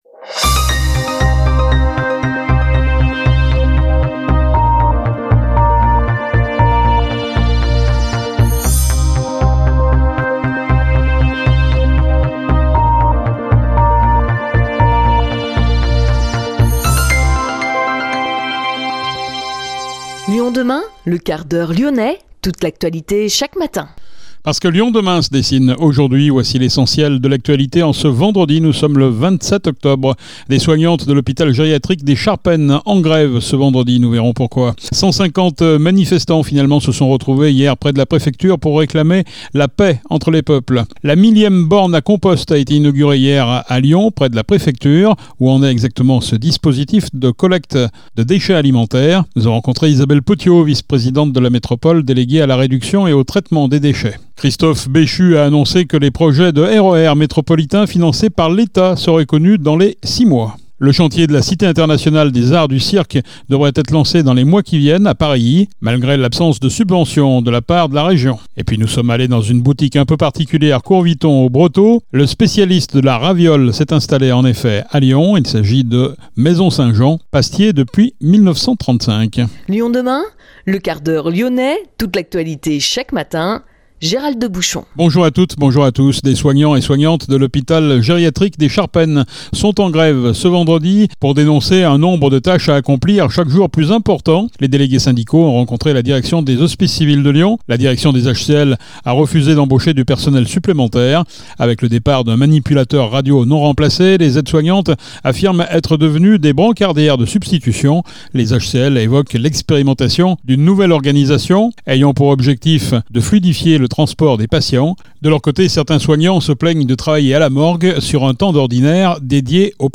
Isabelle Petiot, vice-présidente de la Métropole déléguée à la réduction et au traitement des déchets, est notre invitée